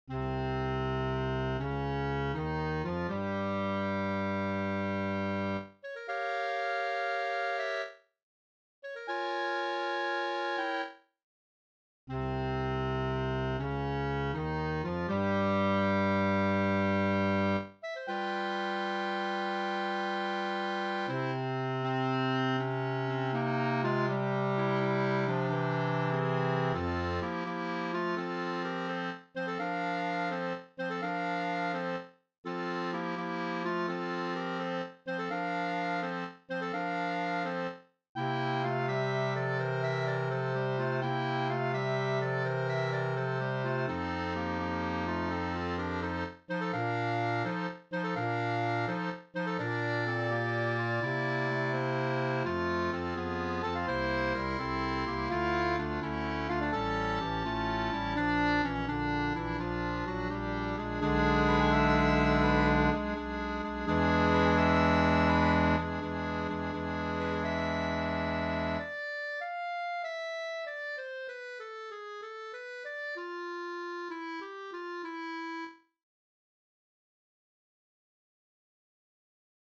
Lots of tonguing and some divisi sections.